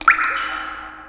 DRIP3.WAV